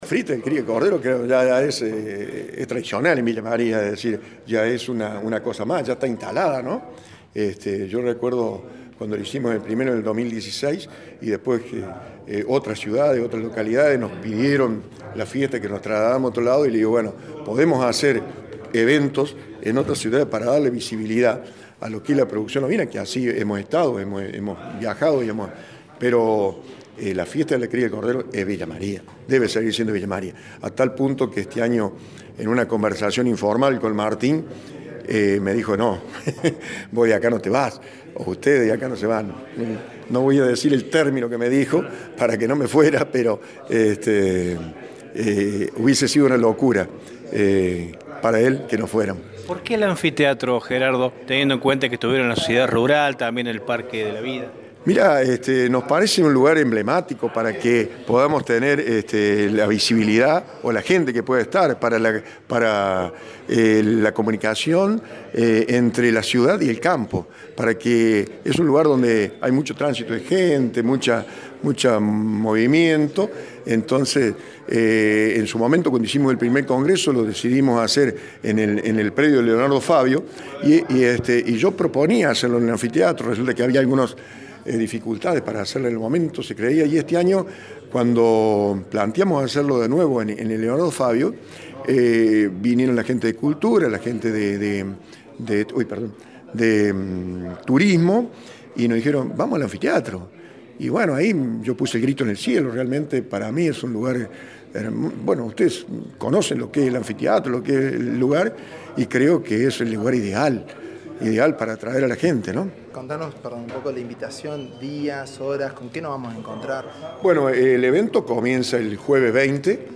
El testimonio